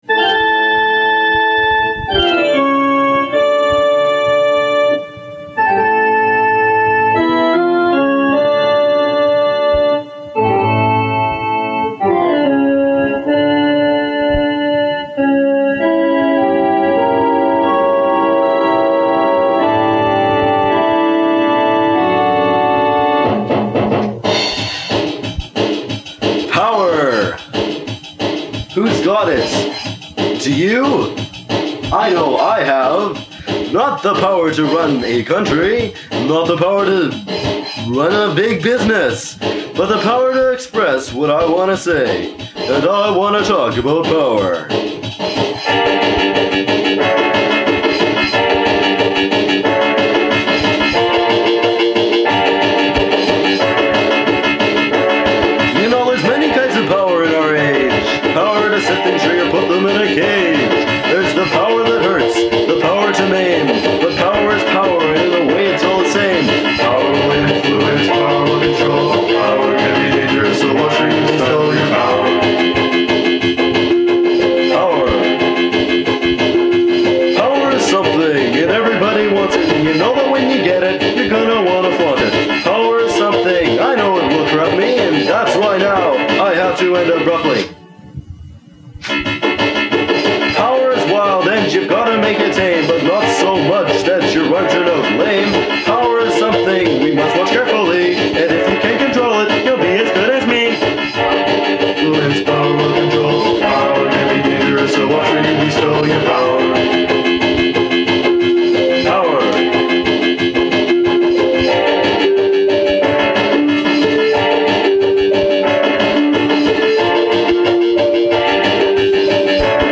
Like all our stuff, this is very high on the cheese scale, but at least it’s trying to say something.
I think my only contribution is the out of tune mumbling during the chorus.